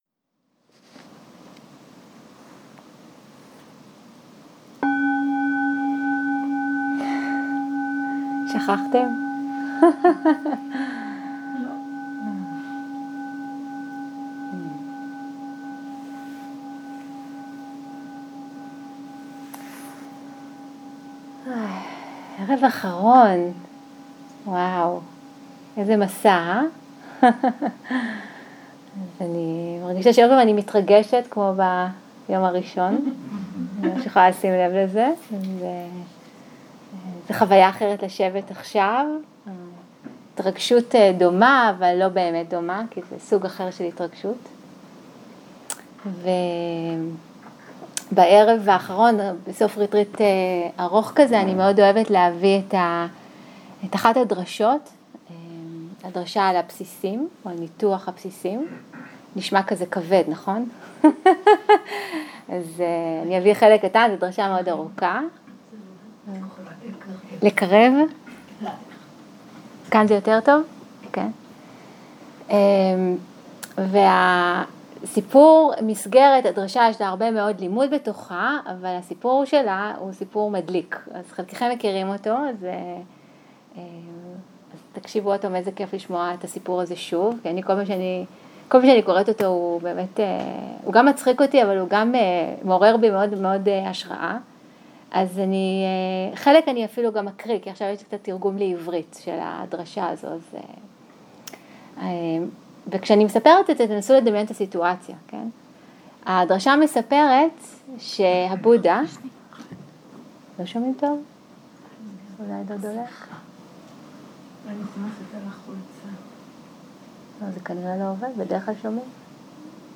יום 9 - ערב - שיחת דהרמה - ארבע העקרונות - הקלטה 23
סוג ההקלטה: שיחות דהרמה